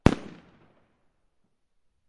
爆炸 " 043 烟花
Tag: 有声 轰的一声 烟花 网络连接recrackers 爆炸